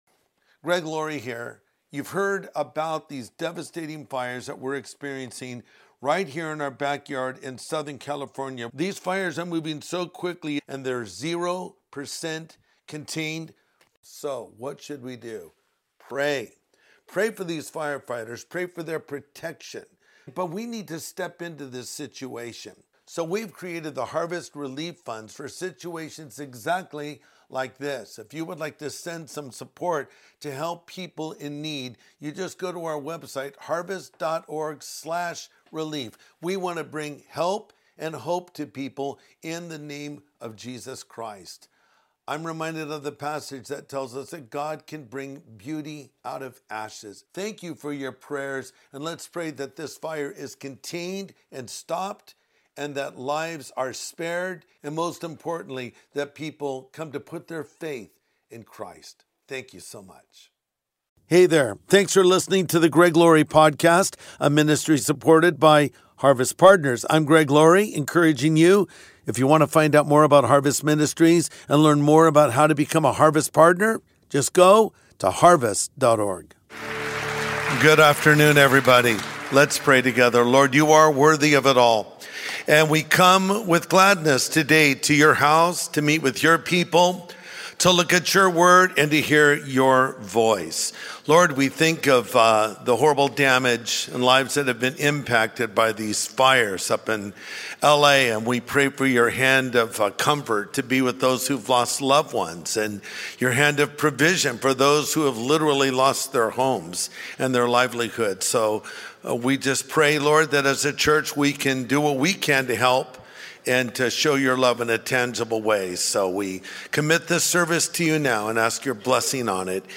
Rebuilding the Ruins of Your Life | Sunday Message